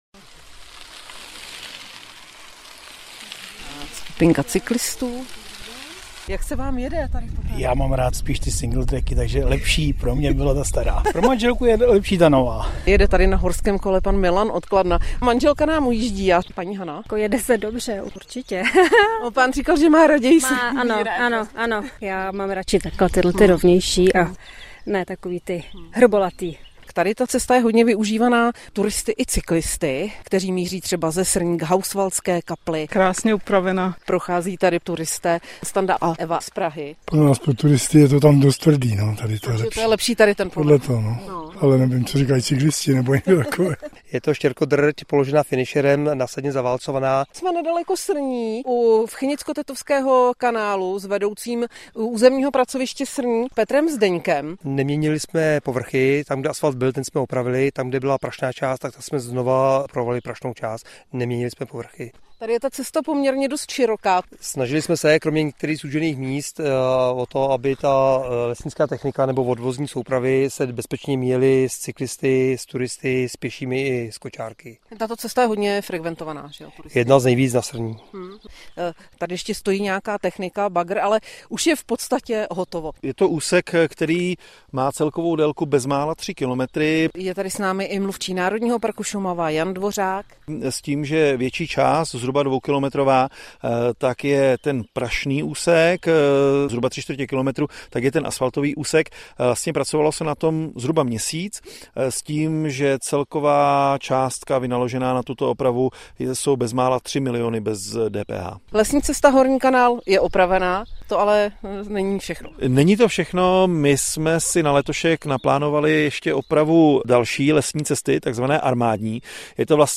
Náš host: Herec Igor Bareš - 21.06.2024